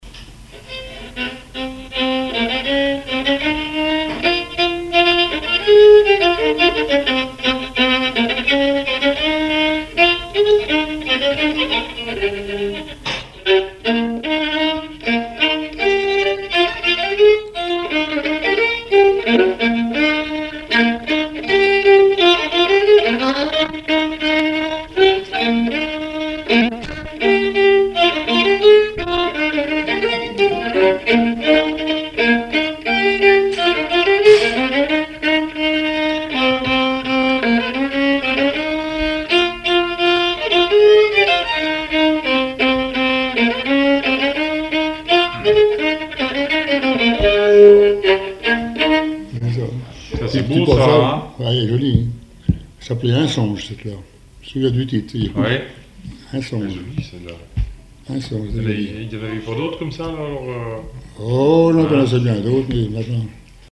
Mémoires et Patrimoines vivants - RaddO est une base de données d'archives iconographiques et sonores.
danse : scottich sept pas
répertoire de bals et de noces
Pièce musicale inédite